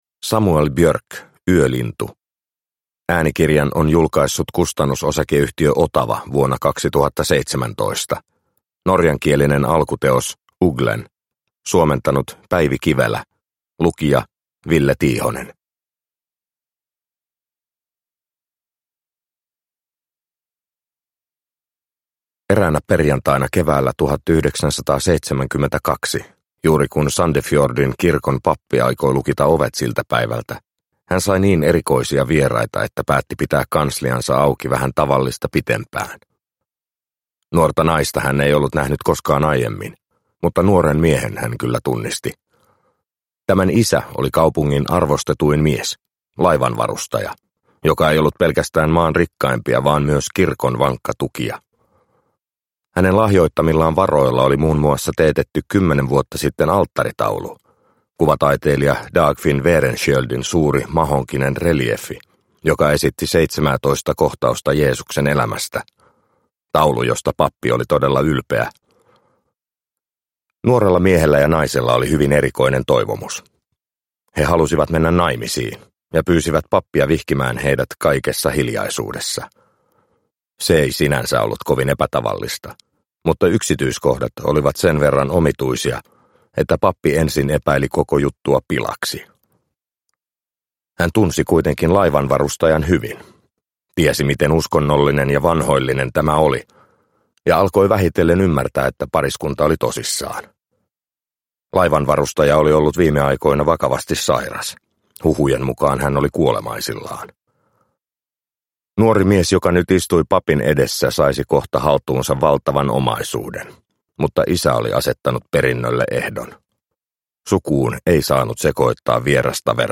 Yölintu – Ljudbok – Laddas ner